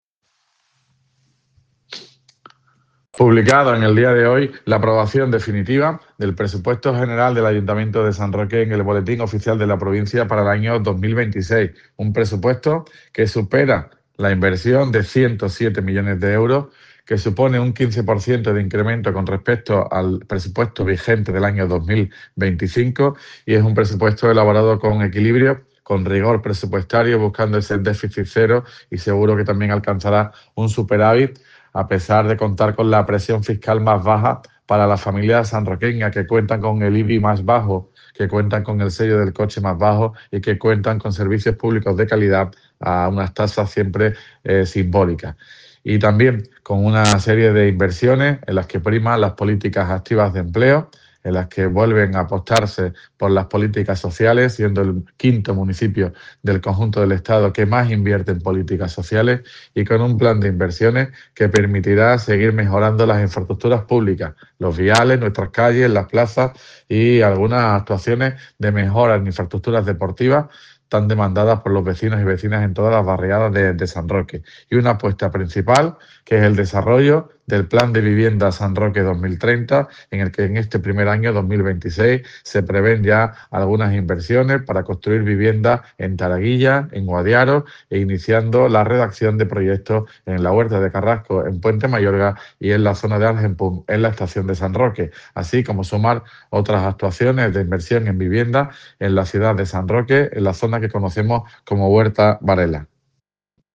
AUDIO_ALCALDE_PRESUPUESTO.mp3